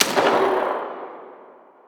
AutoGun_far_01.wav